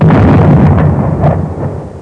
bomb2.mp3